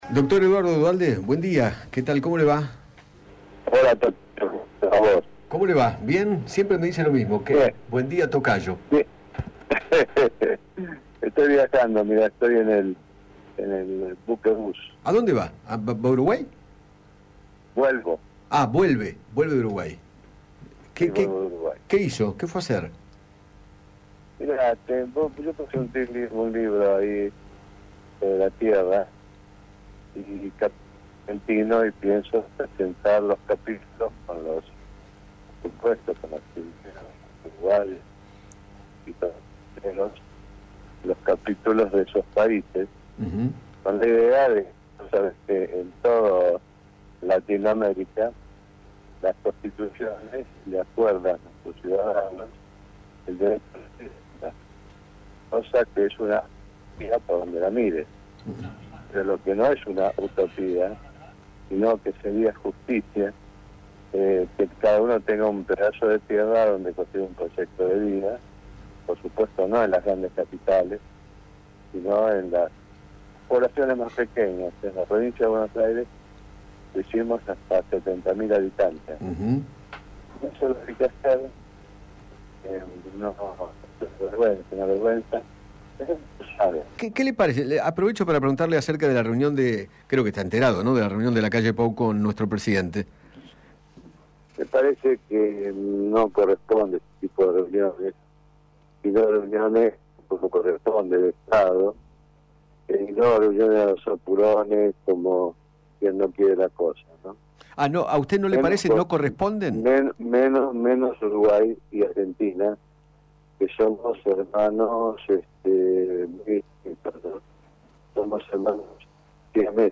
Eduardo Duhalde, ex presidente de la Nación, dialogó con Eduardo Feinmann sobre el encuentro que mantuvieron ambos mandatarios, en la residencia de Parque Anchorena, en la ciudad de Colonia. Además, habló del proyecto de Interrupción Voluntaria del Embarazo (IVE).